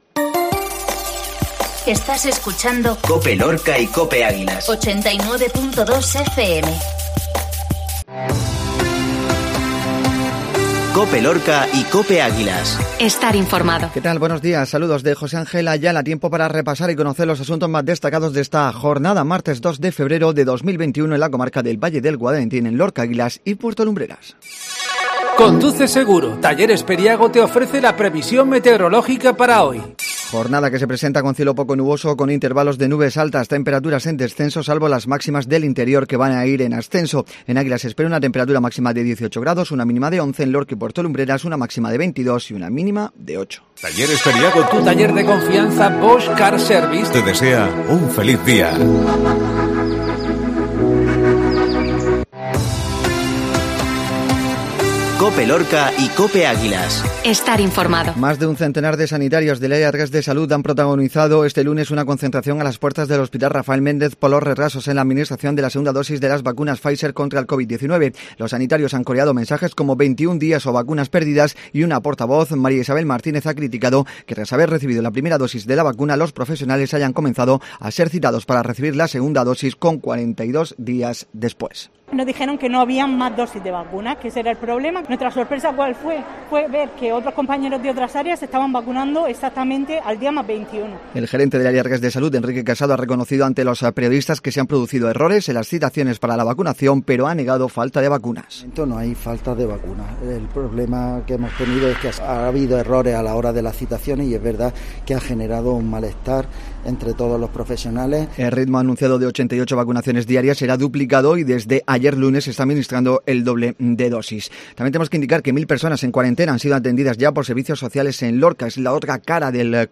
INFORMATIVO MATINAL MARTES